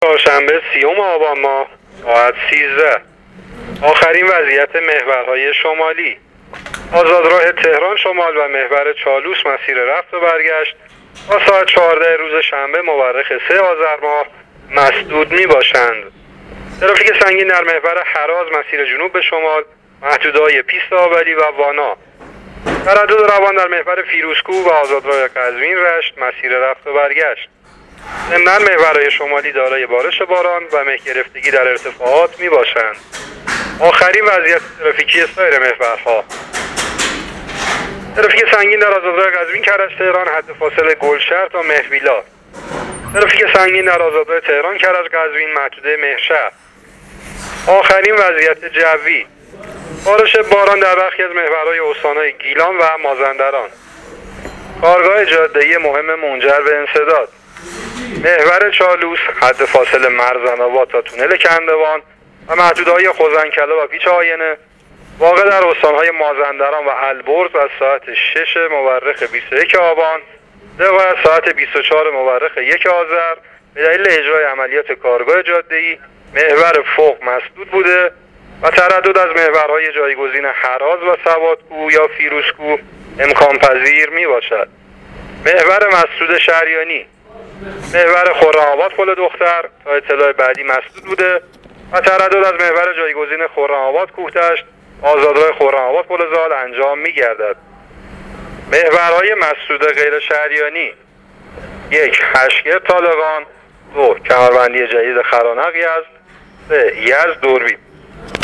گزارش آخرین وضعیت ترافیکی جاده‌های کشور را از رادیو اینترنتی پایگاه خبری وزارت راه و شهرسازی بشنوید.